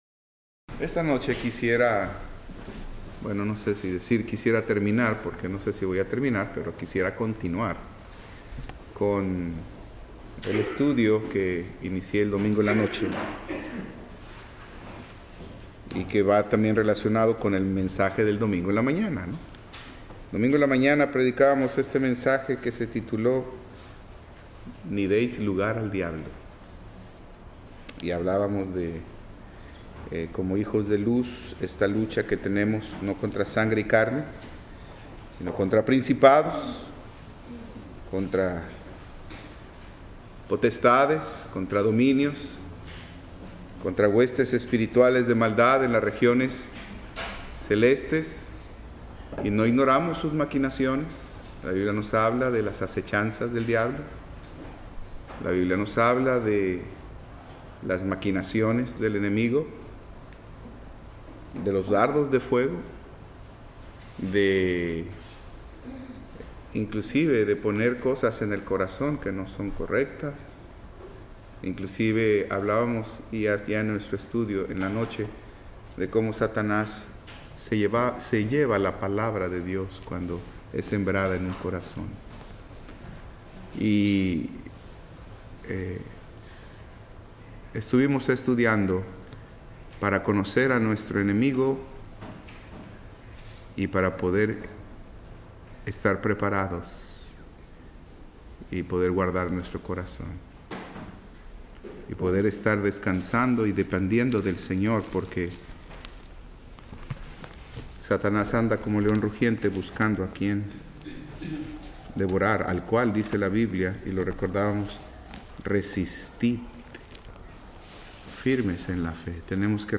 Servicio miércoles